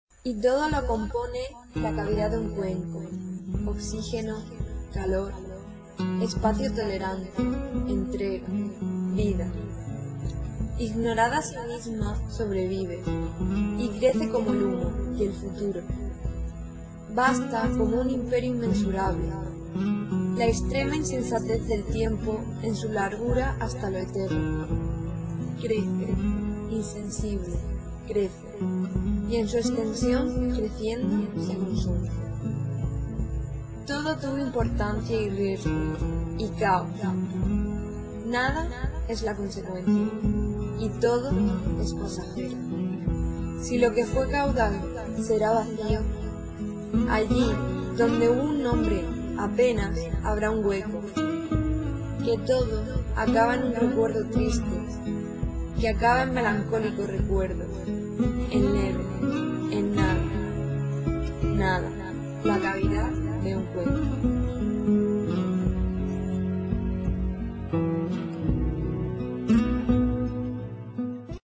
Inicio Multimedia Audiopoemas La cavidad de un cuenco.